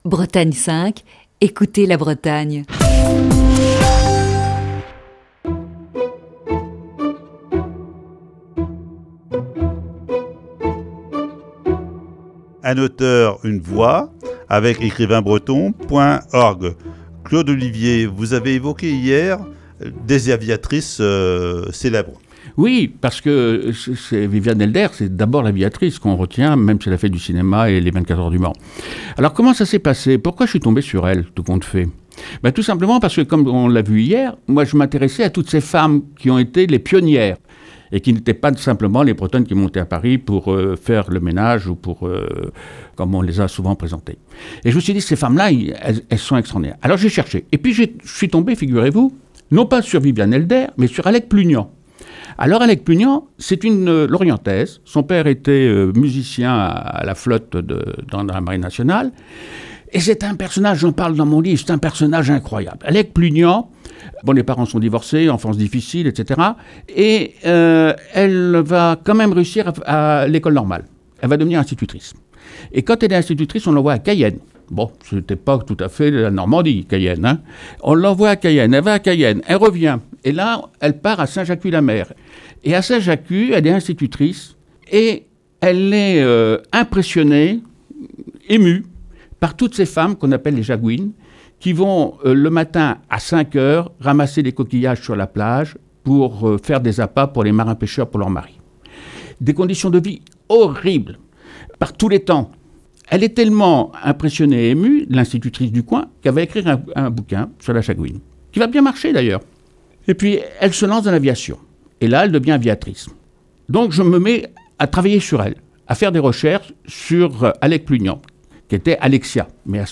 Voici ce vendredi la cinquième et dernière partie de cet entretien diffusé le 6 mars 2020.